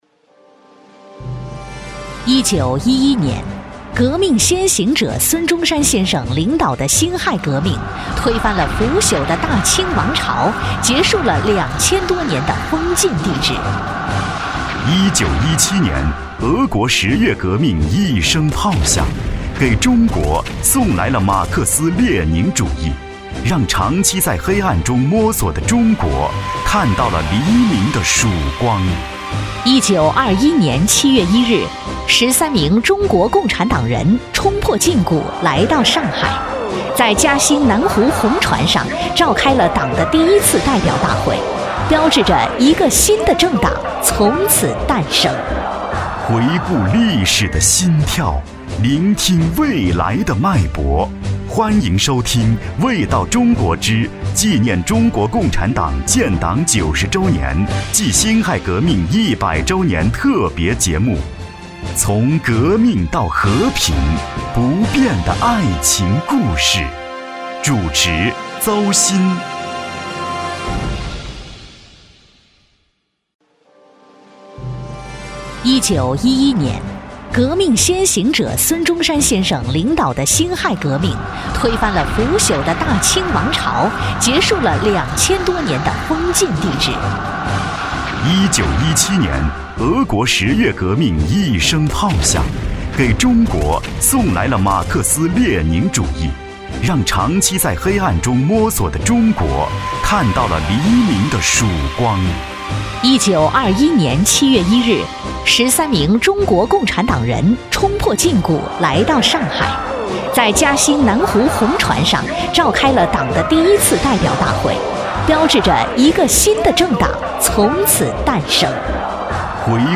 国语青年大气浑厚磁性 、积极向上 、男专题片 、宣传片 、50元/分钟男S39 国语 男声 宣传片-巨人文化传媒-传媒企业宣传片-成熟大气 大气浑厚磁性|积极向上